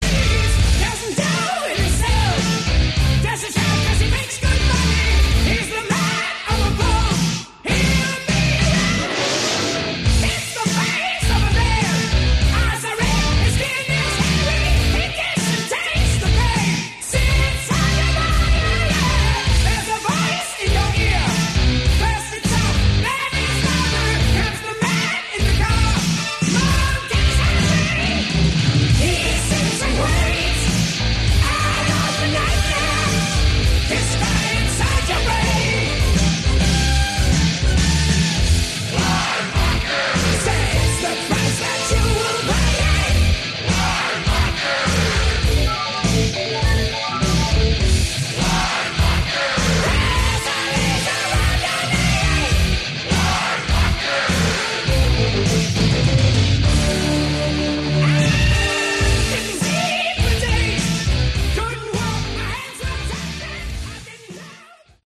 Category: Hard Rock
lead and backing vocals
keyboards
guitar
drums